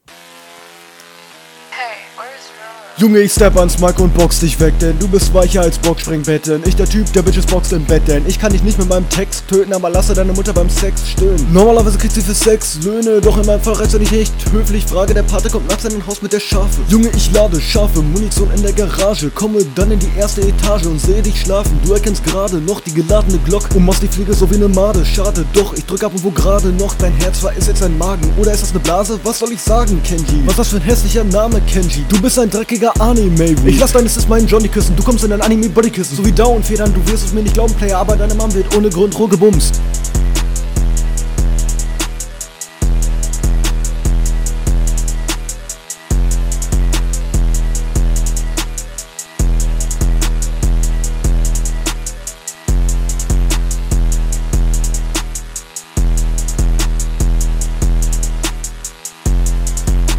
Was mit direkt aufgefallen ist, ist das die Silben bisschen überzogen bzw. einfach nicht gepasst …